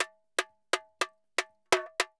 Tamborin Baion 120_1.wav